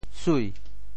潮州府城POJ súi 国际音标 [sui]
sui2.mp3